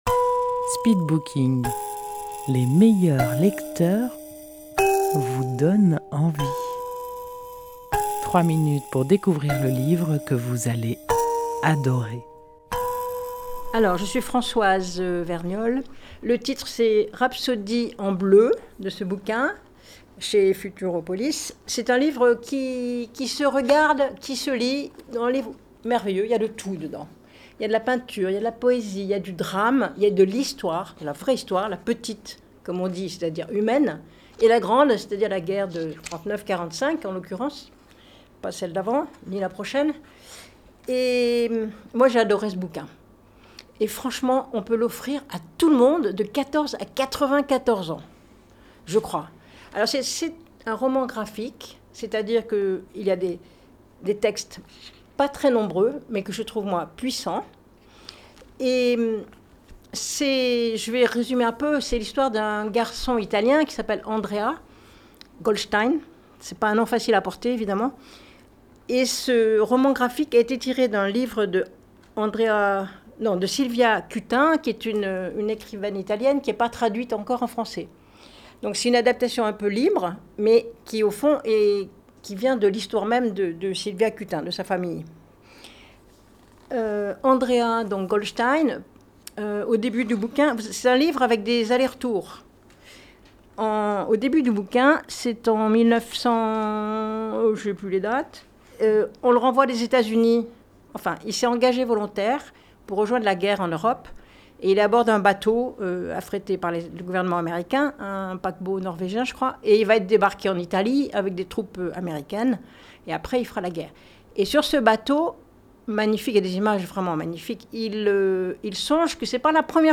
Speed booking Rendez-vous au speed booking : les meilleurs lecteurs vous font partager leur passion pour un livre en 3 minutes chrono et en public. Enregistré en public au salon de thé Si le cœur vous en dit à Dieulefit.